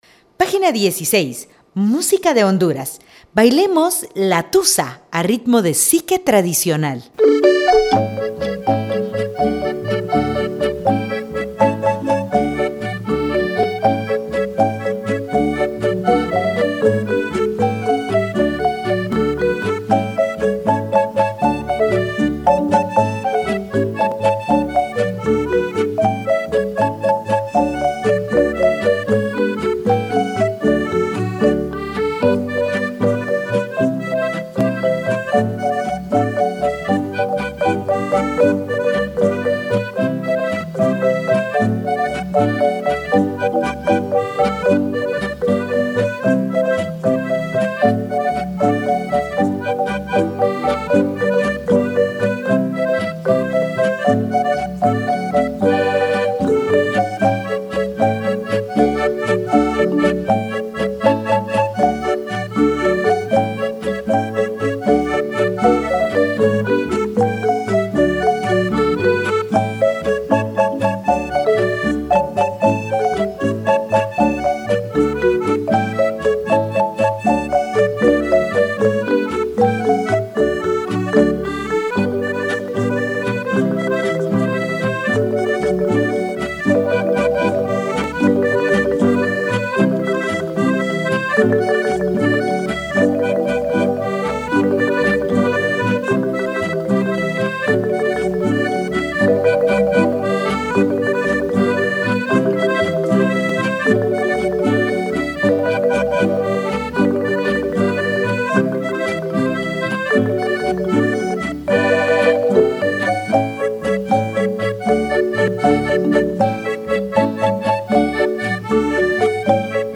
- Xique